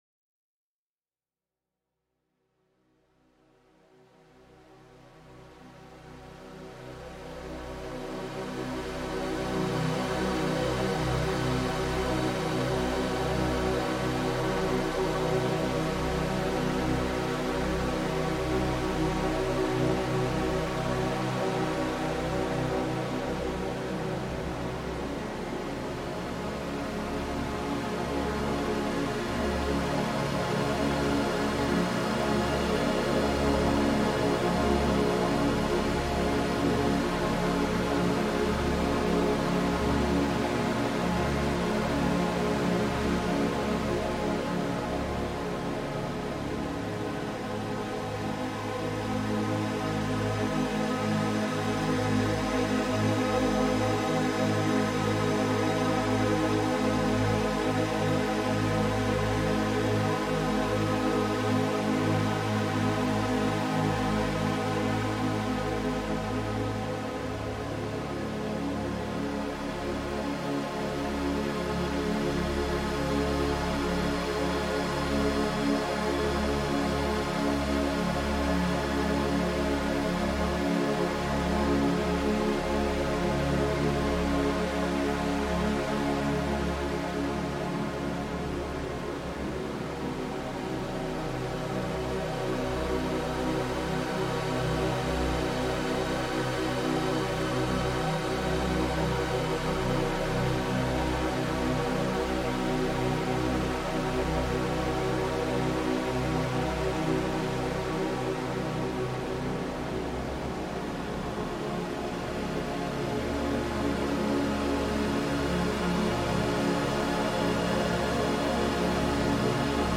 Abundance Awakening: Your Prosperity Meditation Journey